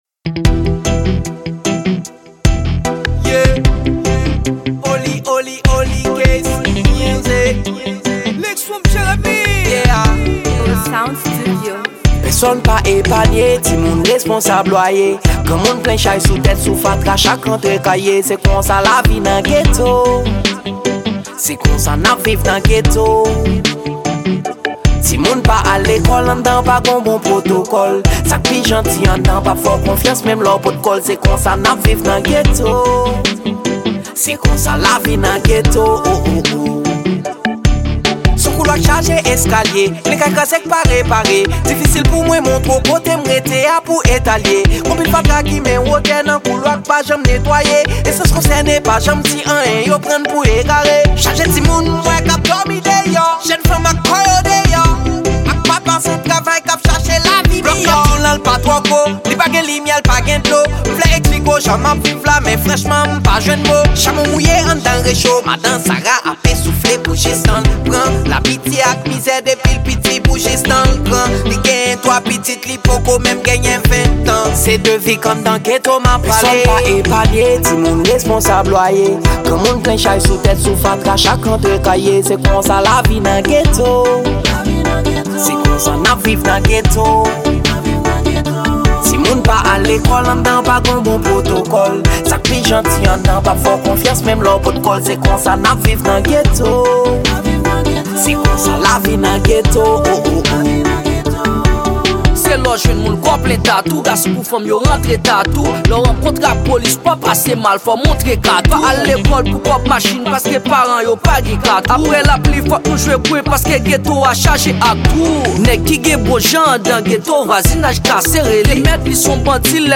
Genre: Reggea.